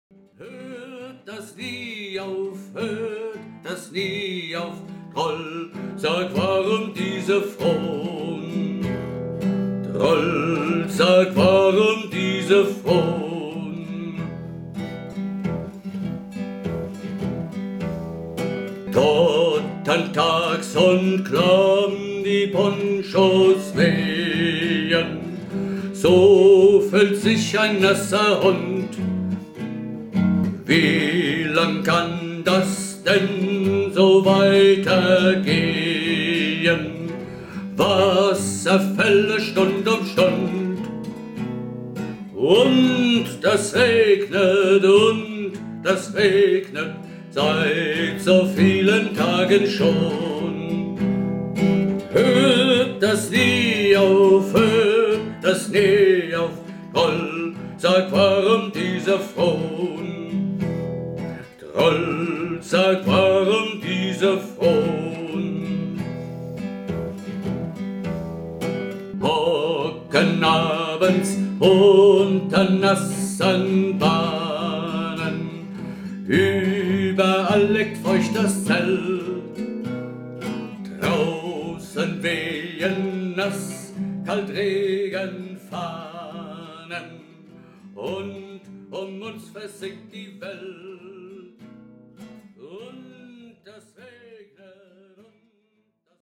MIDI - 1-stimmig